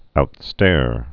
(out-stâr)